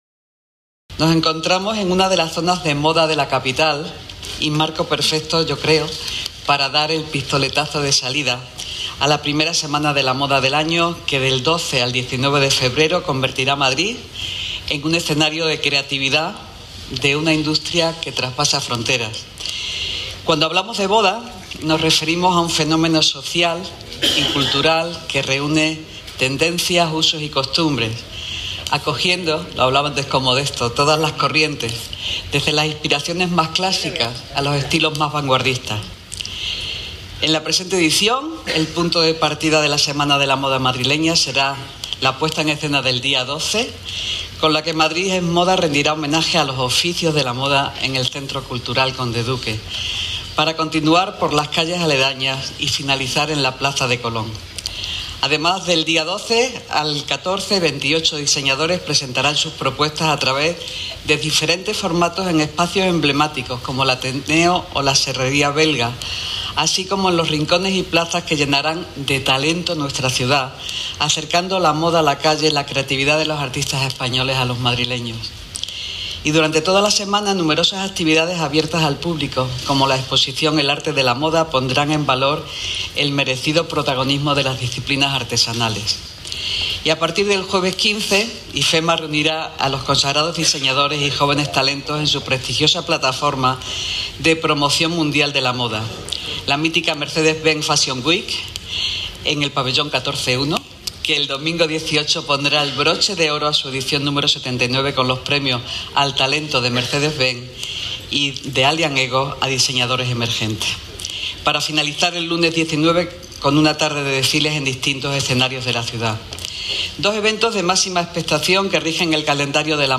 Nueva ventana:Declaraciones de la delegada de Economía, Innovación y Hacienda, Engracia Hidalgo